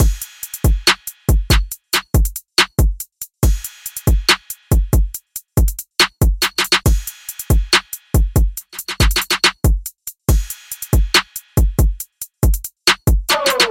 陷阱鼓循环
描述：140bpm